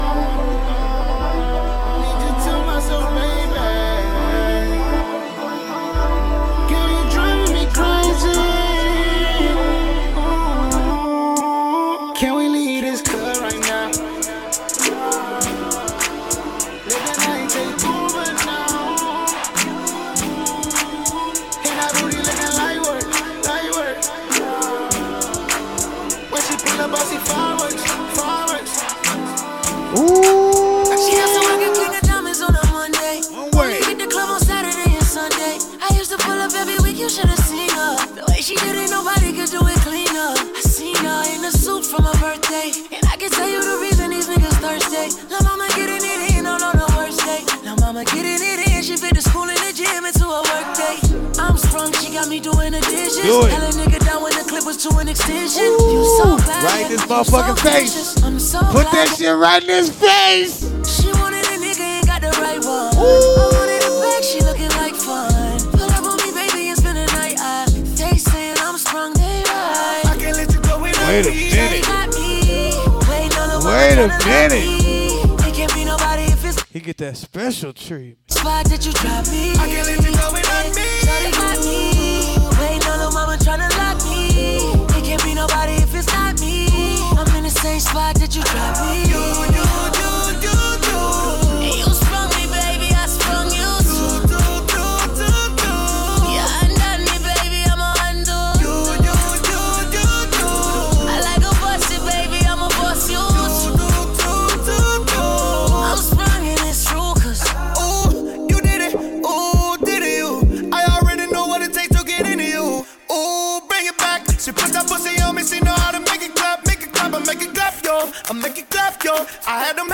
This isn’t your average music talk. It’s street-level perspective meets industry experience—served with energy, humor, and a little bit of smoke.